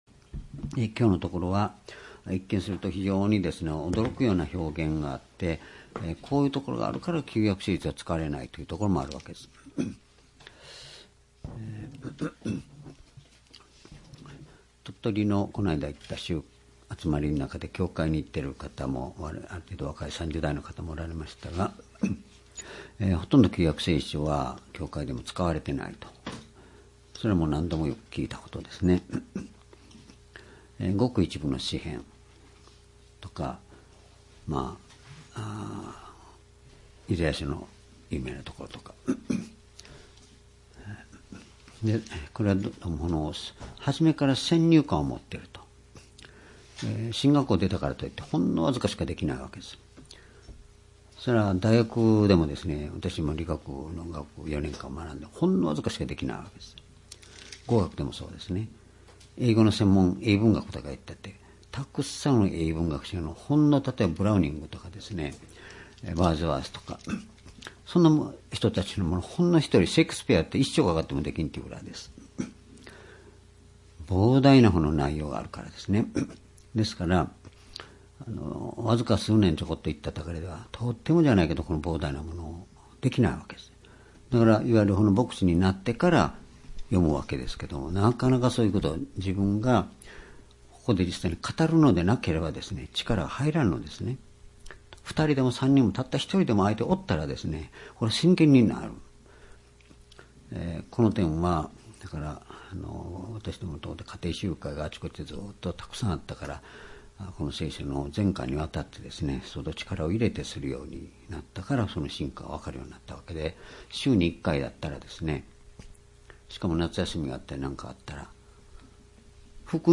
｢｣士師記4章 2019年11月19日 夕拝
（主日・夕拝）礼拝日時 2019年11月19日 夕拝 聖書講話箇所 「 」 士師記4章 ※視聴できない場合は をクリックしてください。